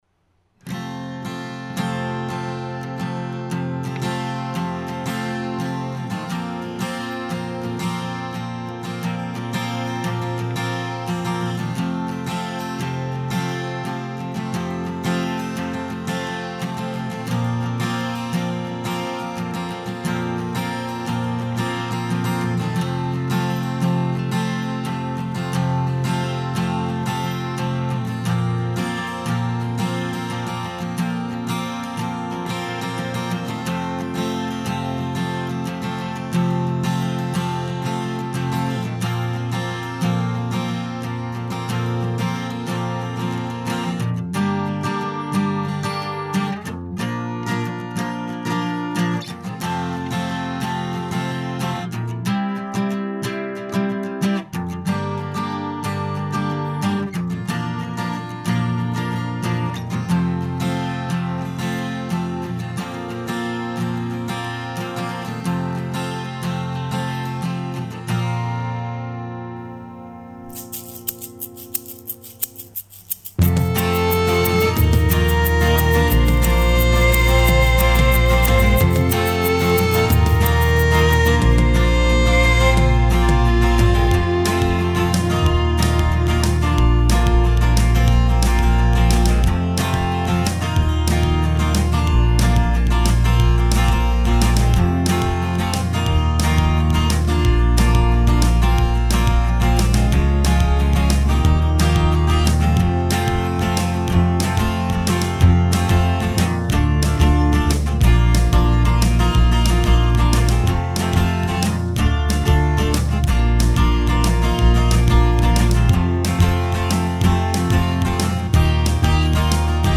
voz
teclado, guitarra y batería midi